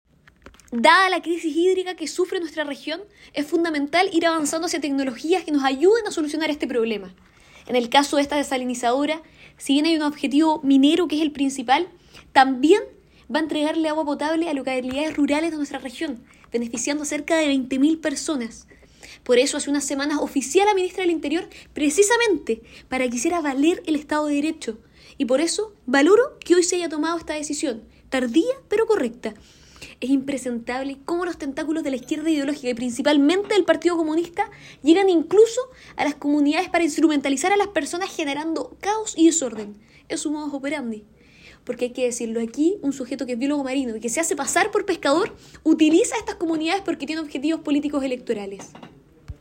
La diputada Chiara Barchiesi se pronunció respecto al desalojo vivido esta mañana en la planta desaladora de Quintero. En sus declaraciones, la diputada destacó la importancia de avanzar hacia tecnologías que ayuden a solucionar la crisis hídrica que sufre la región.